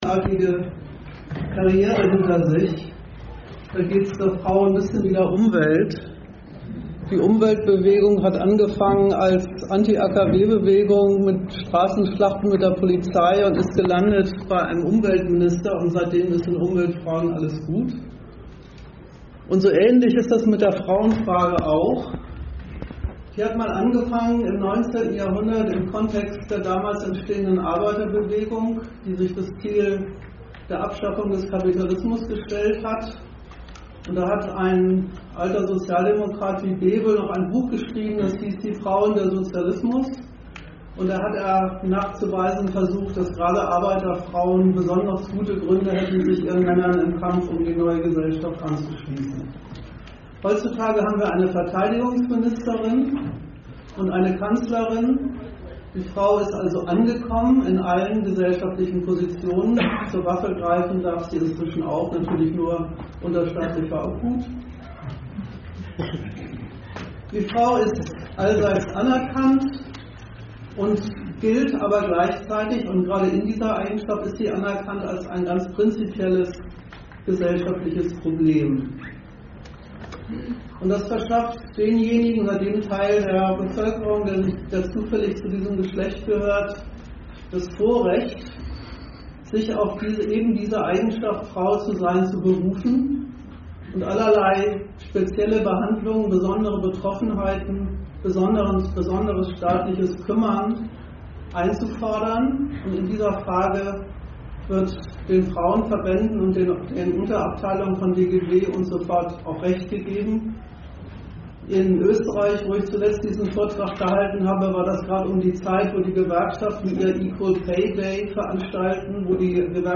Dozent Gastreferenten der Zeitschrift GegenStandpunkt